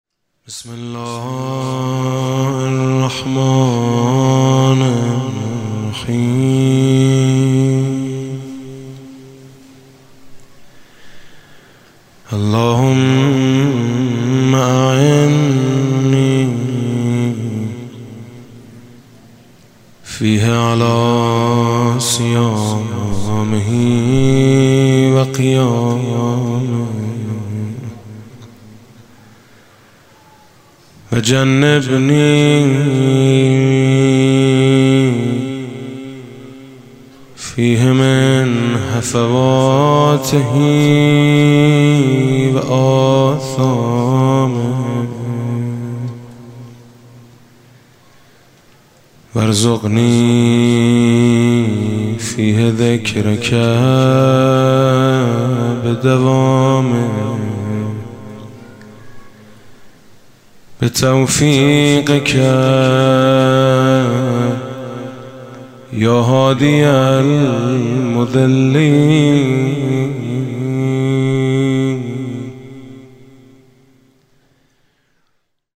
music-icon دعا و زیارت: دعای روز هفتم ماه مبارک رمضان حاج میثم مطیعی